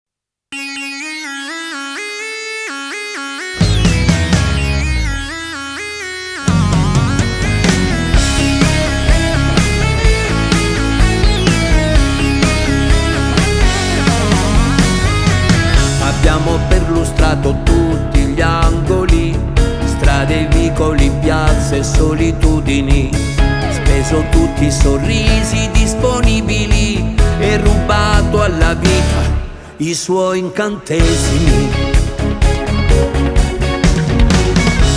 Скачать припев
2025-09-12 Жанр: Поп музыка Длительность